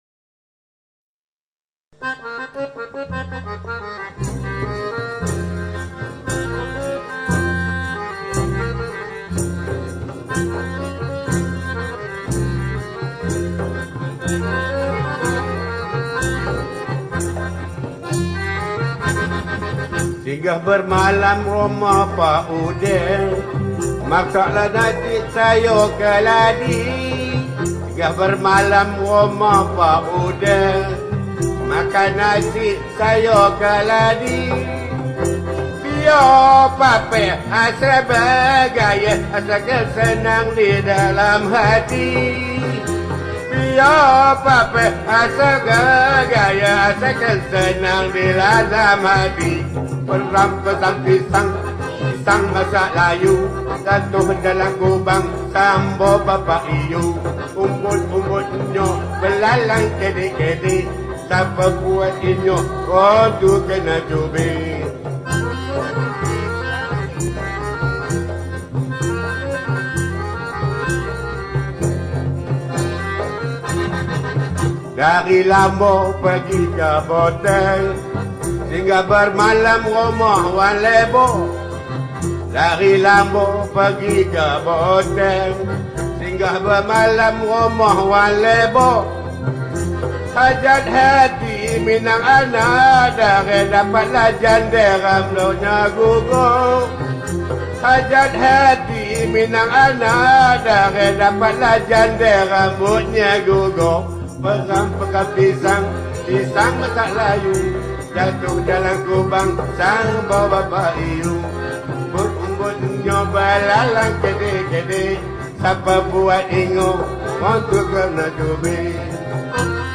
Lagu Rakyat Negeri Perak
Skor Angklung